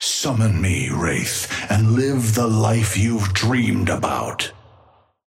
Amber Hand voice line - Summon me, Wraith, and live the life you've dreamed about.
Patron_male_ally_wraith_start_03.mp3